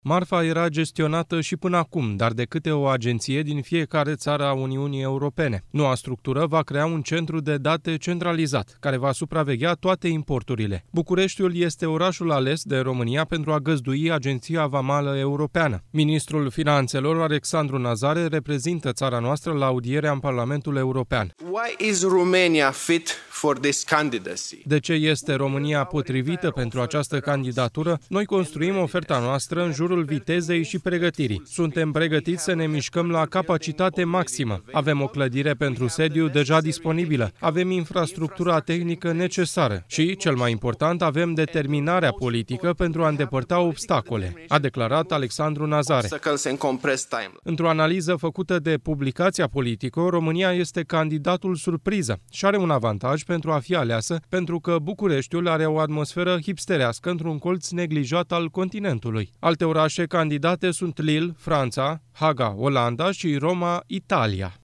Ministrul Finanțelor, Alexandru Nazare, reprezintă țara noastră la audierea în Parlamentul European.